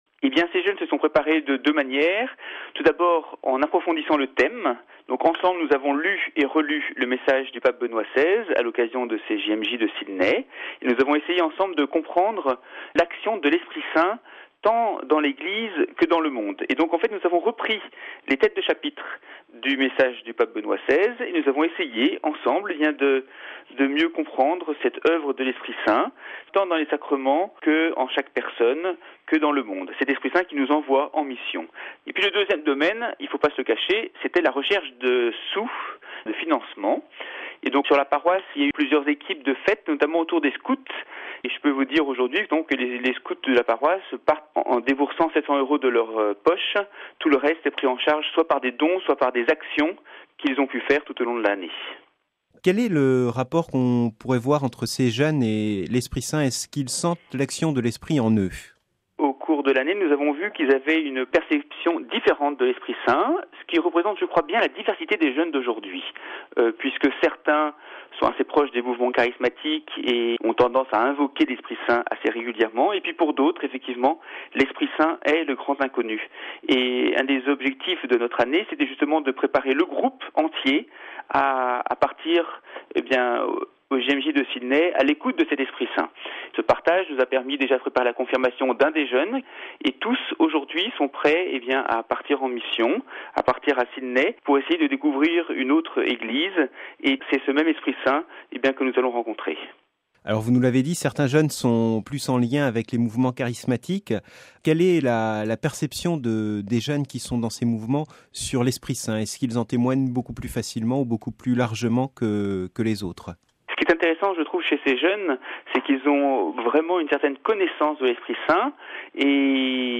Retrouvez dans cette rubrique tous les témoignages diffusés dans nos émissions au sujet des JMJ de Sydney et des JMJ en général.